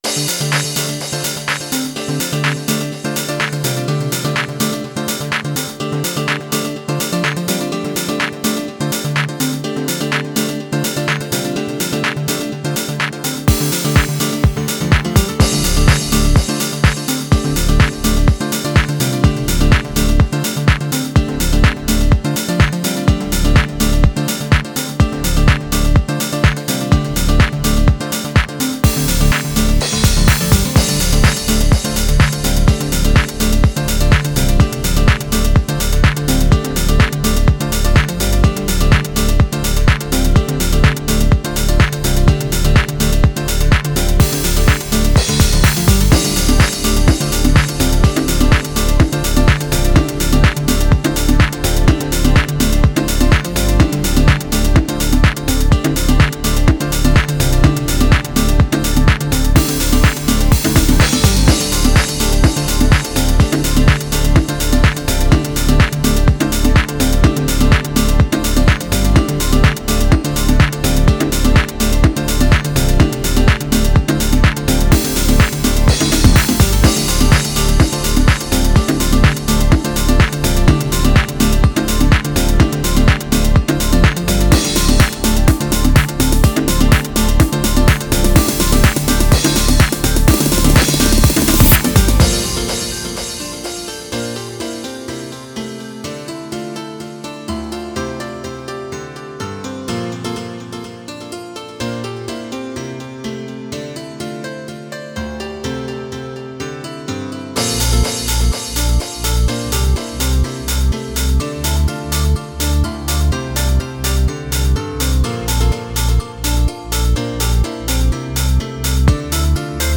HOUSE（BPM１２５）
イメージ：人生　ジャンル：Dream House、Piano House